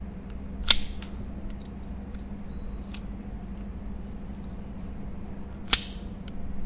SFX图书馆类项目VS " 门锁解锁两次
描述：解锁并锁定门两次
标签： 锁定 解锁
声道立体声